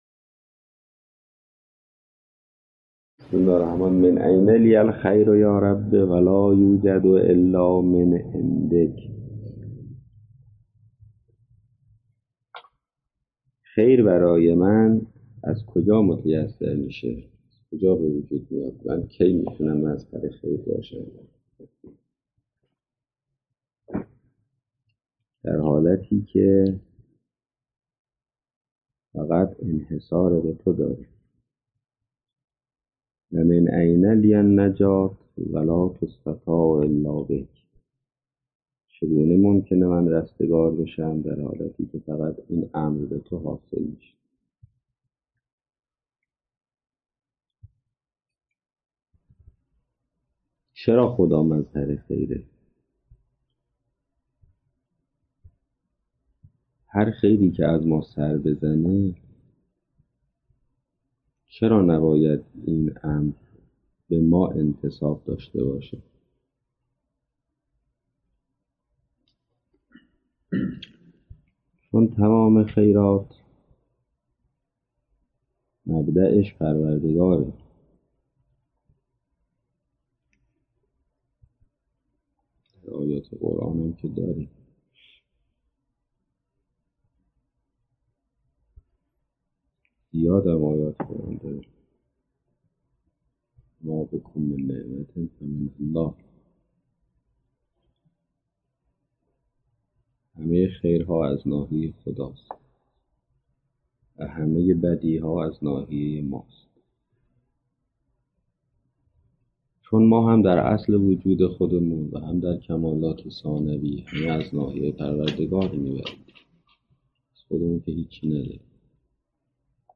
سخنران
سخنرانی